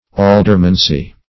Aldermancy \Al"der*man*cy\, n.